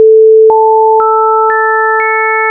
Ülemises reas siinushelid sagedusega 1) 440, 2) 880, 3) 1320, 4) 1760, 5) 2200 Hz, alumises reas liidetuna: 1) ainult põhitoon, 2) ühe, 3) kahe, 4) kolme ja 5) nelja ülemheliga.
liitheli440X5.wav